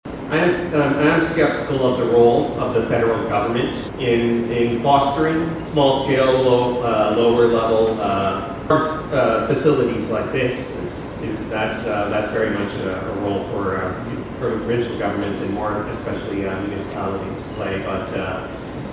VALLEY VOTES: Algonquin-Renfrew-Pembroke federal candidates debate at Festival Hall L’Equinox a success